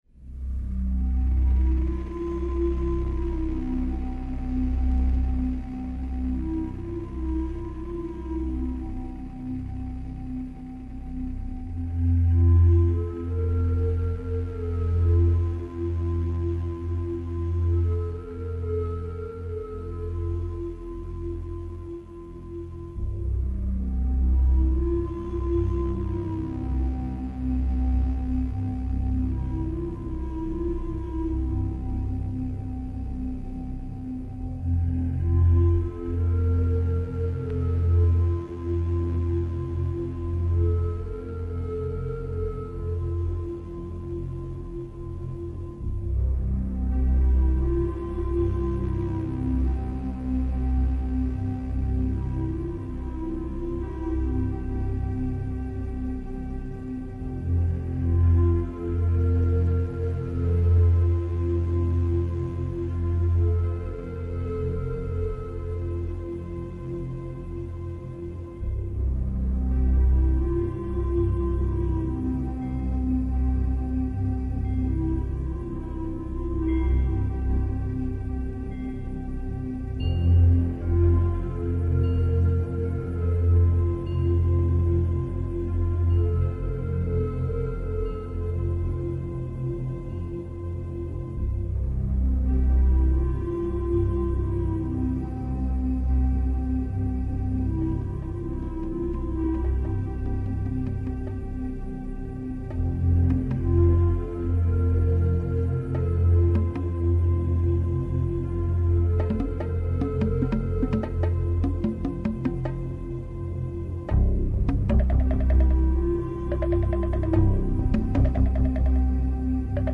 New Age Музыка шаманов